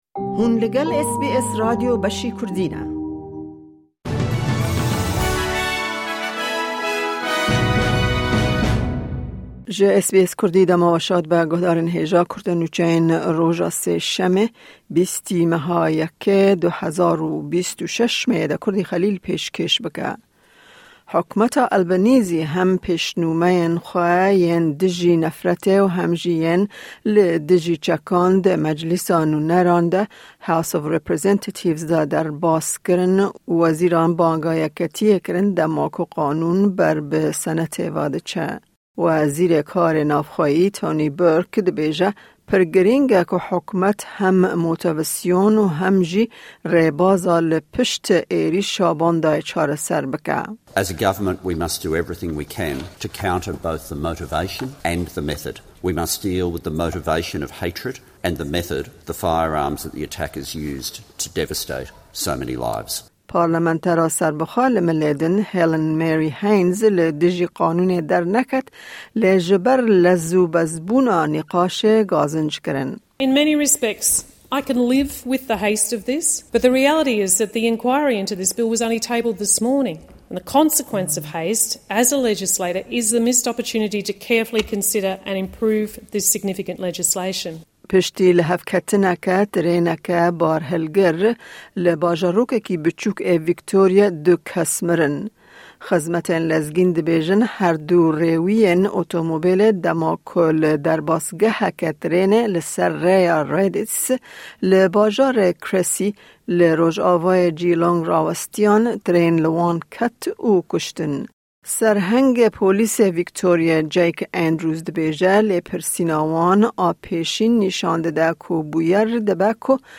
Nûçeyên roja Sêşemê 20/01/2026